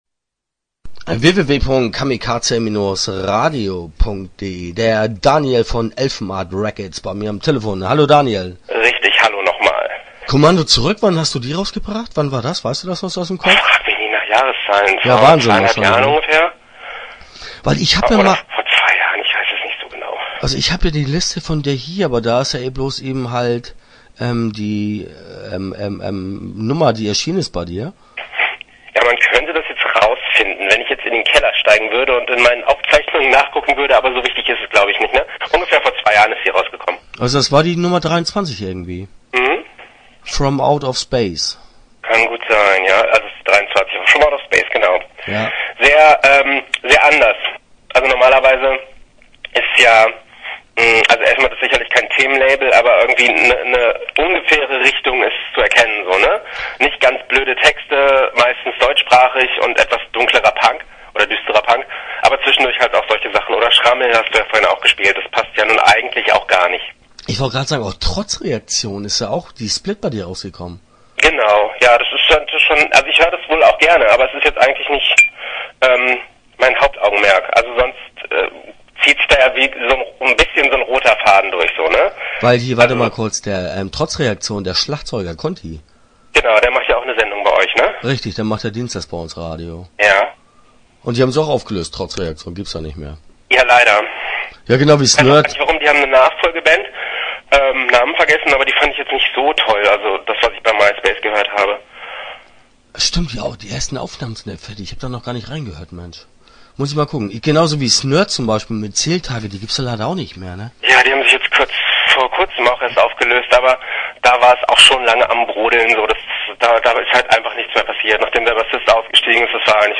Start » Interviews » Elfenart Records